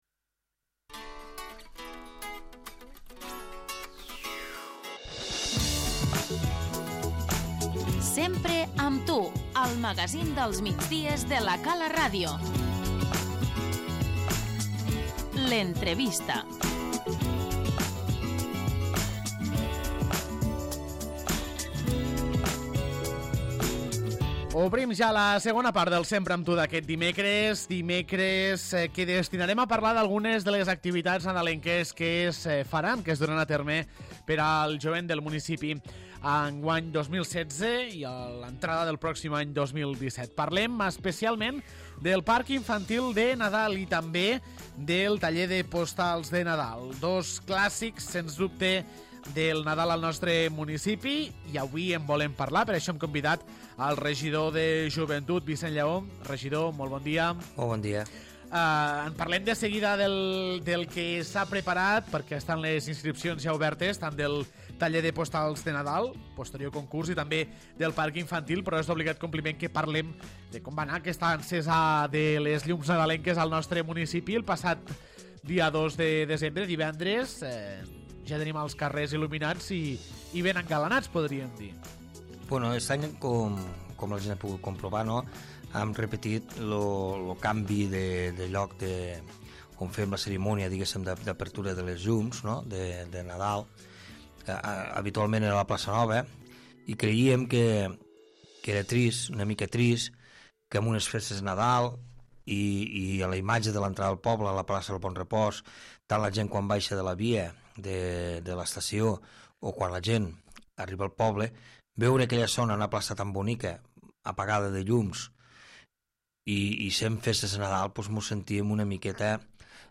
L'entrevista - Vicenç Llaó, regidor de Joventut
El regidor de Joventut de l'Ametlla de Mar, Vicenç Llaó, és el convidat d'avui a l'Entrevista per parlar de dues cites tradicionals i molt nadalenques a la població: el Parc Infantil de Nadal, i el taller de Postals de Nadal i el concurs.